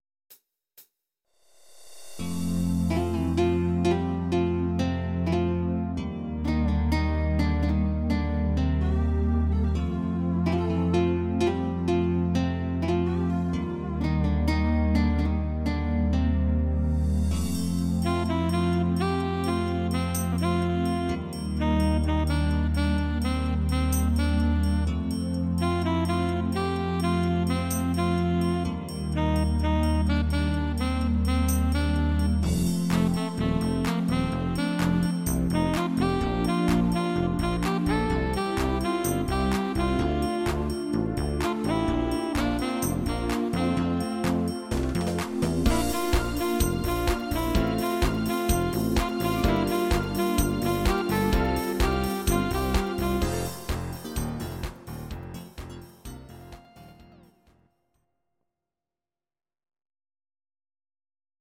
Audio Recordings based on Midi-files
Pop, Musical/Film/TV, 1990s